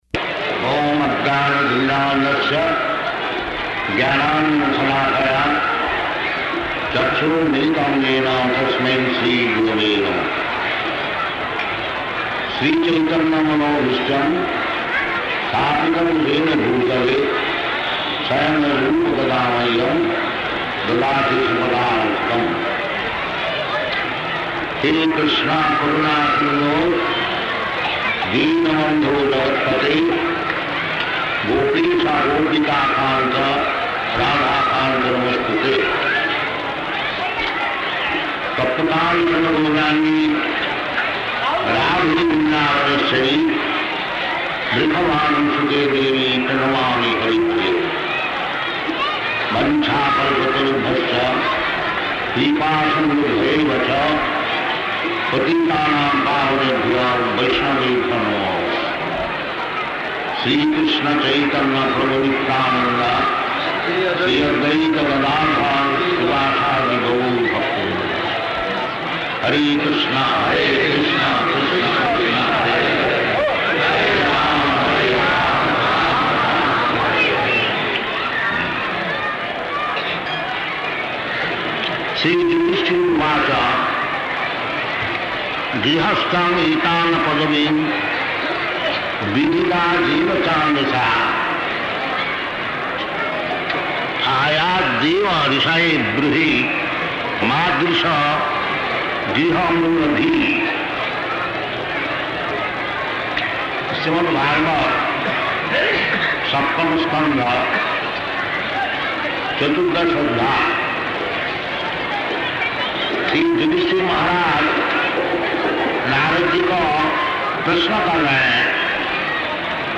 Lecture in Hindi
Type: Lectures and Addresses
Location: Vṛndāvana